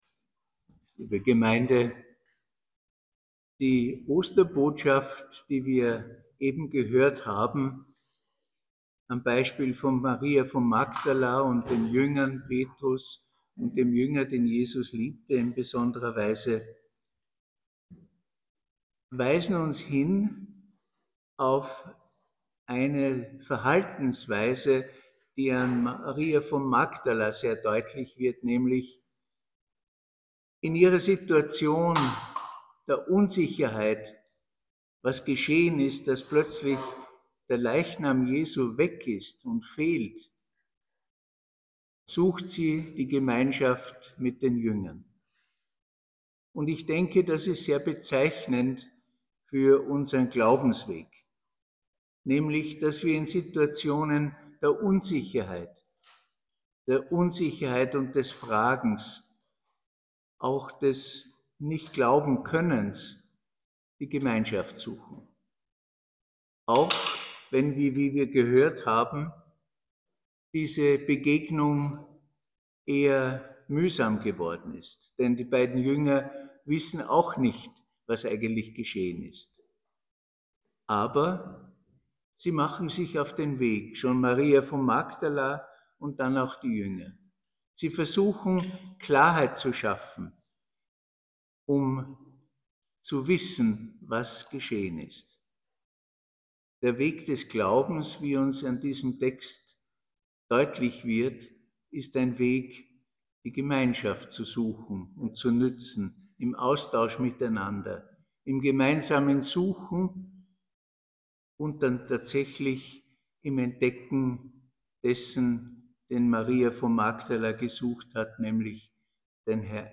Worte zur Schrift.
Ruprechtskirche